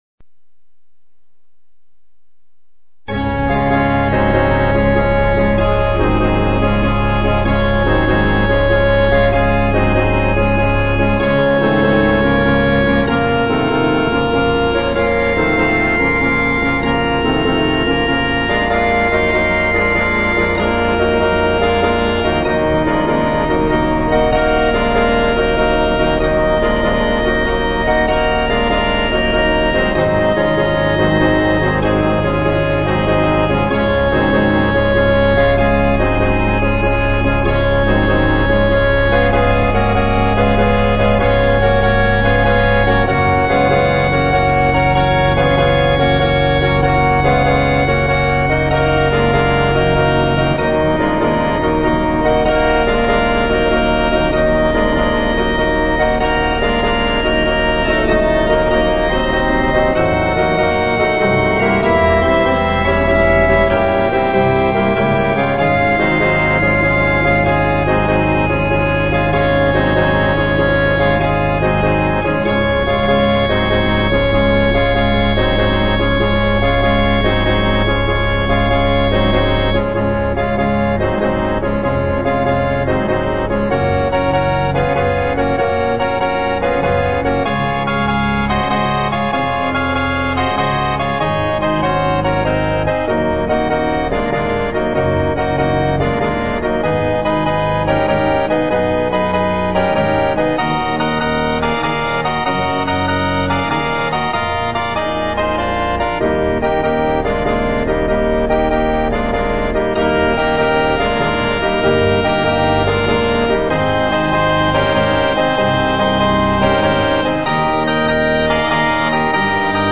Allegro for Organ in D flat major
This baroque-style piece, dated 1965, is a juvenile composition.
widely used in the organ literature.